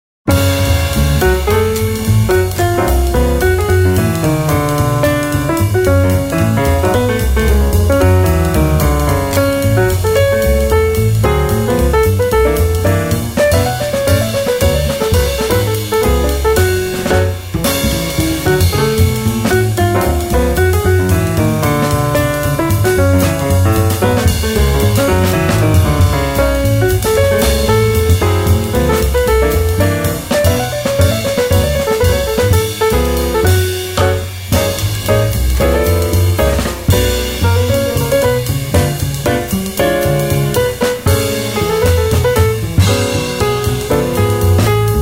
piano
bass
drums
美しきクラシックのスタンダード・チューンをス インギンなジャズのフィーリングで力強く、そして優雅にプレイ！